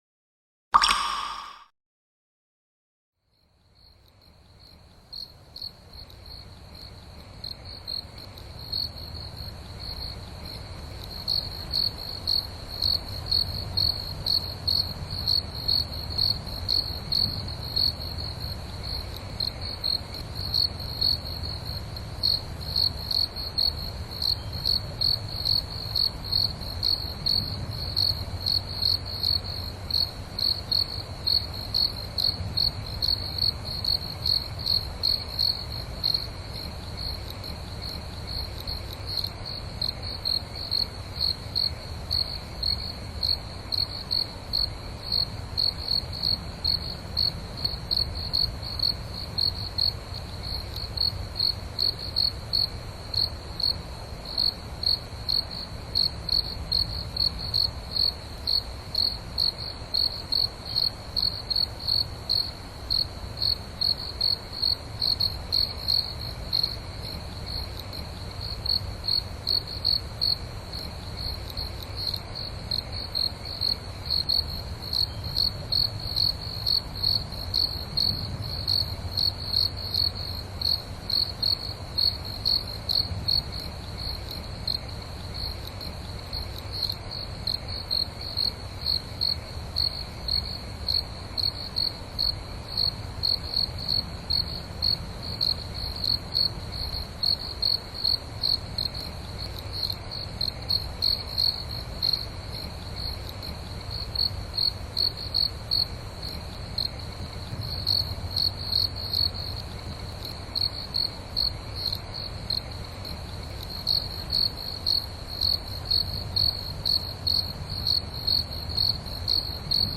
Feuerknistern-Flammen: Herzberuhigung durch mystisches Knistern